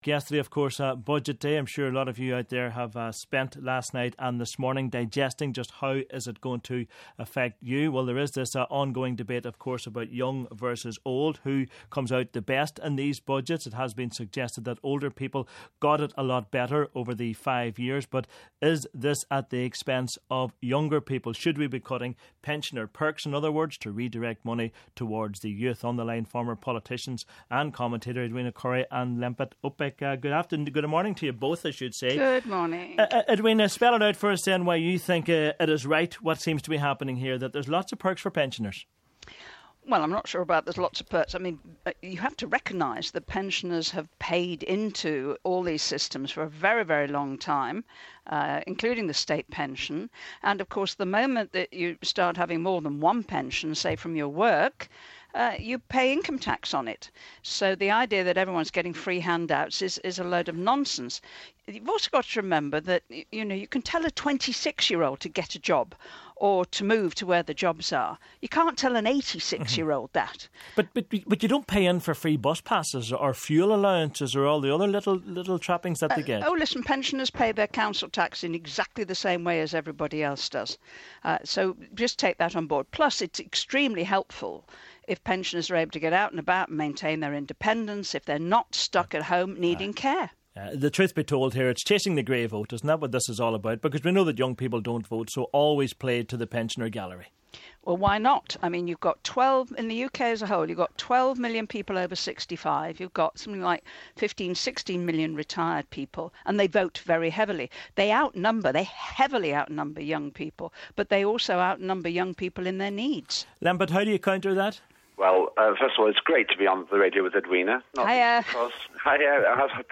former politicians and commentators Edwina Currie and Lembit Opik